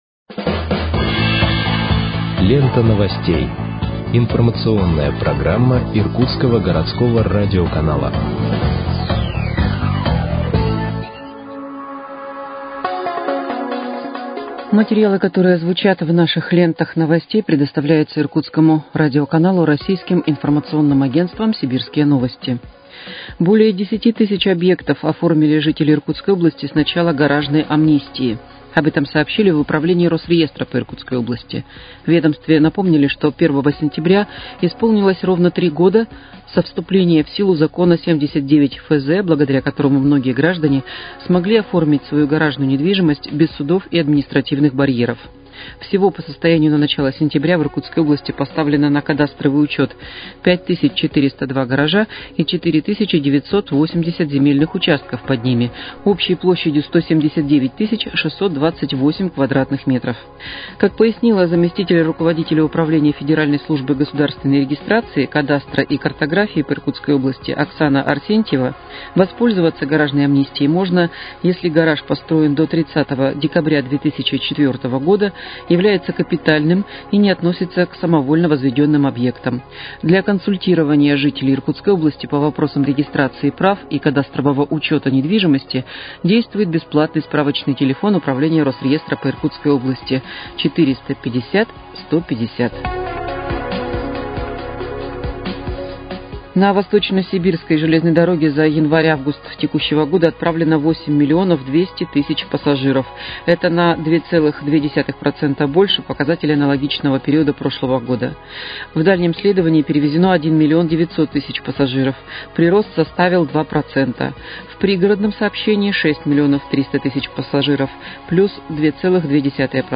Выпуск новостей в подкастах газеты «Иркутск» от 09.09.2024 № 1